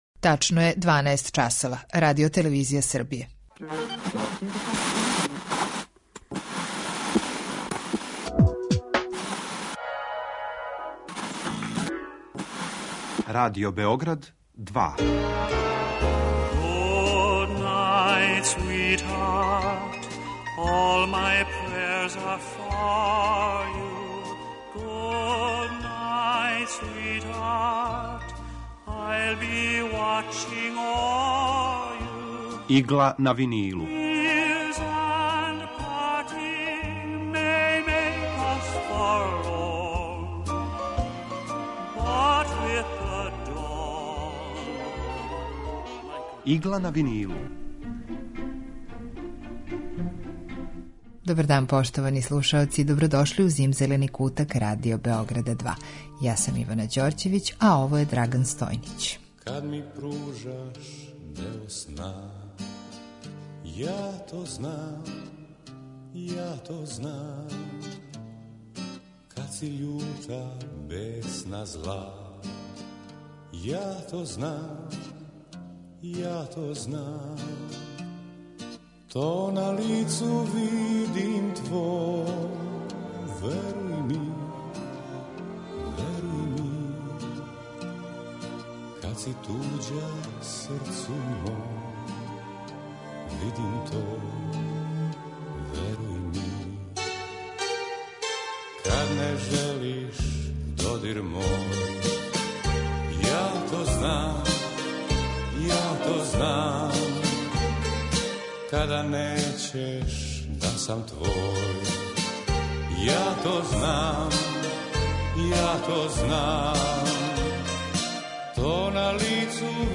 Емисија евергрин музика